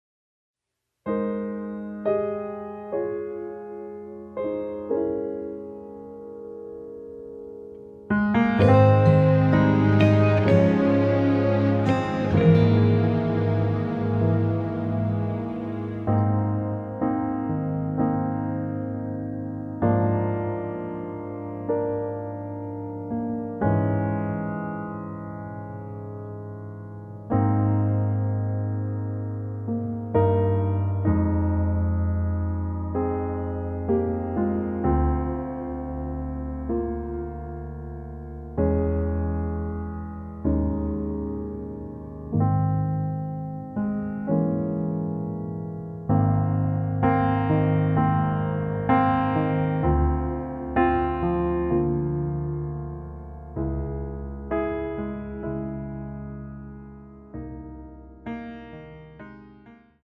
[공식 음원 MR]
키 Ab 가수